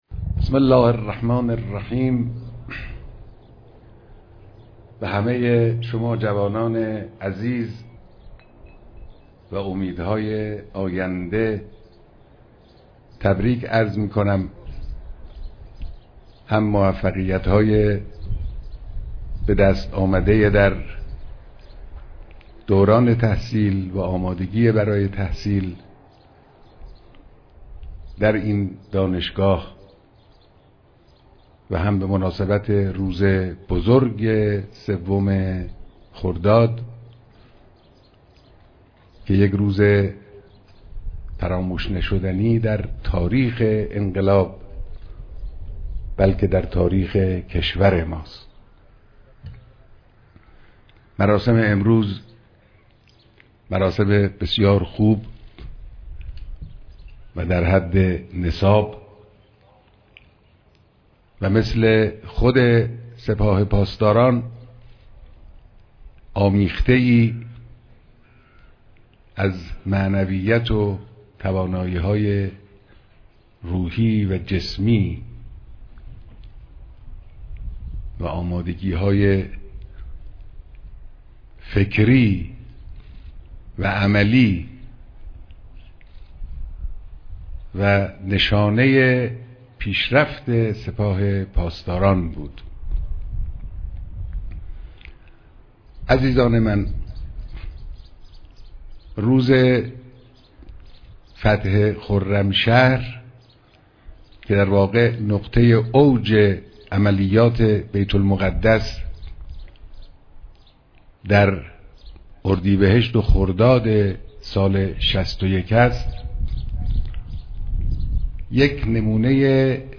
مراسم دانش آموختگی دانشگاه افسری و تربیت پاسداری امام حسین(ع)